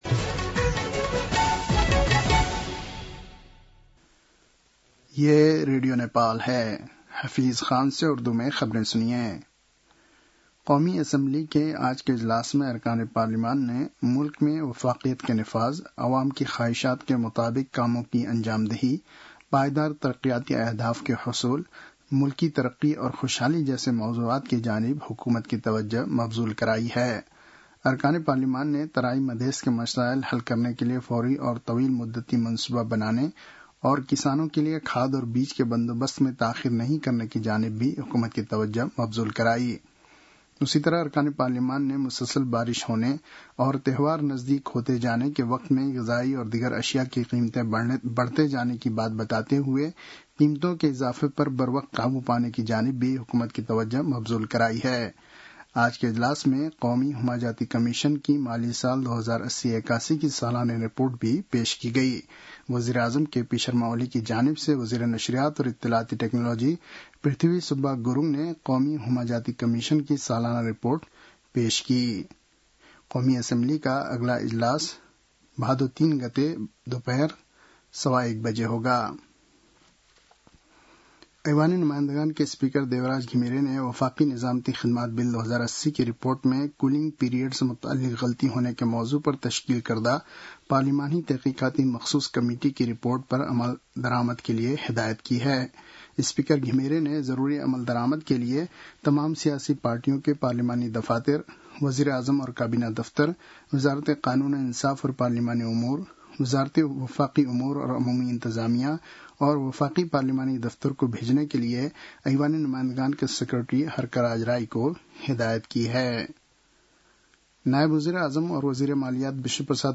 उर्दु भाषामा समाचार : ३० साउन , २०८२
Urdu-NEWS-04-30.mp3